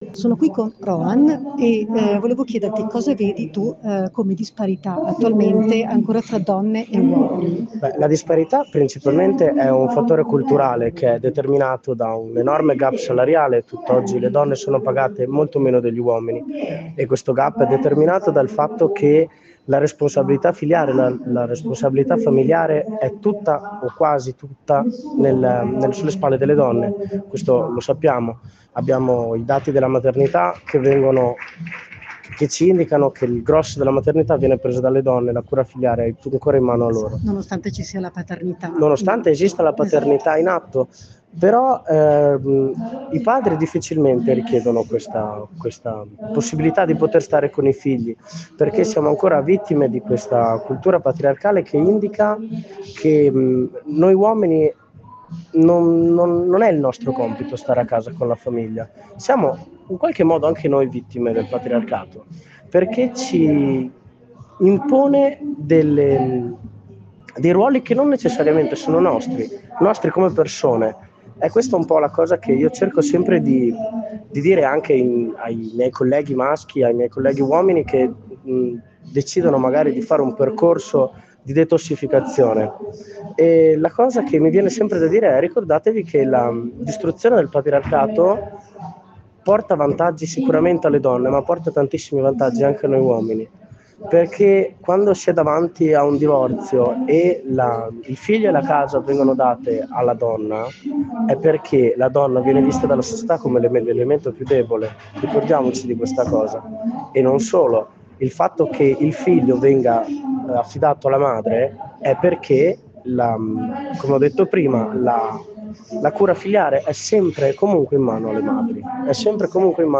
IN PIAZZA DEI MARTIRI LA VOCE DELLE DONNE